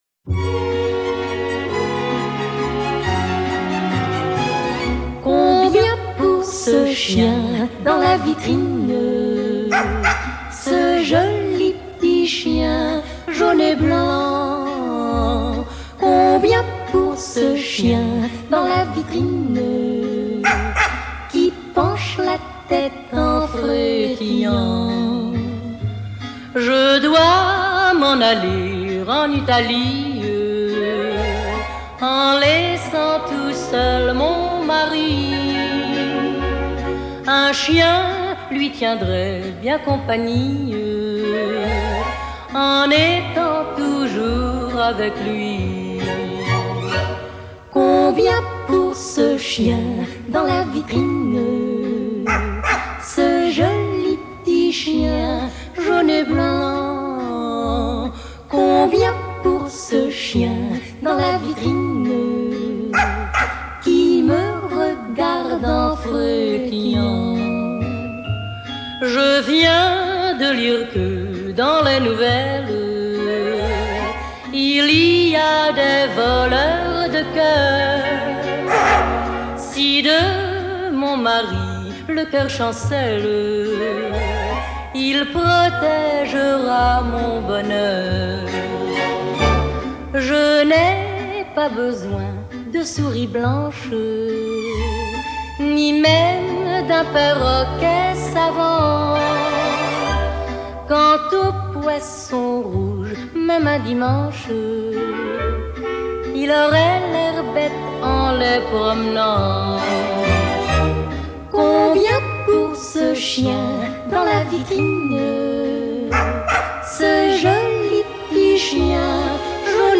Ça gratte, le son est un peu tordu, mais c’est magique.